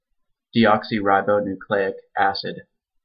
Deoxyribonucleic acid (/dˈɒksɪˌrbnjˌklɪk, -ˌkl-/
En-us-Deoxyribonucleic_acid.ogg.mp3